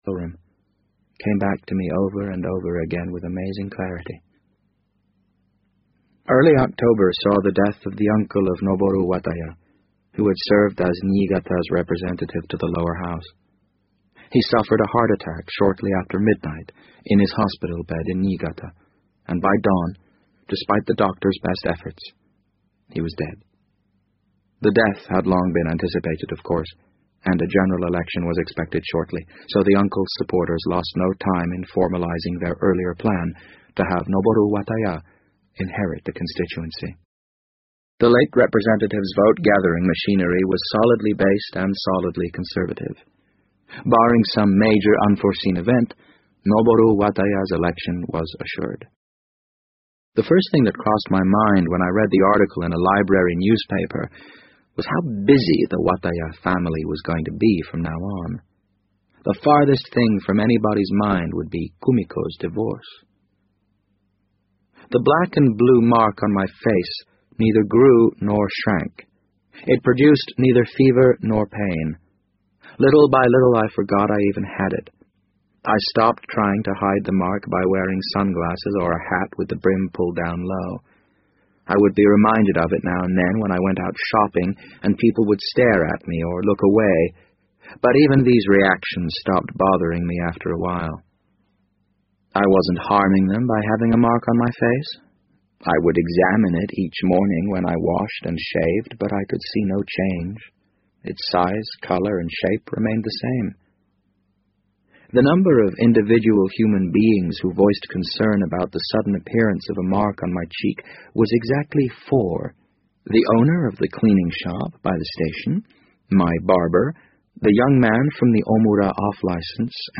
BBC英文广播剧在线听 The Wind Up Bird 009 - 7 听力文件下载—在线英语听力室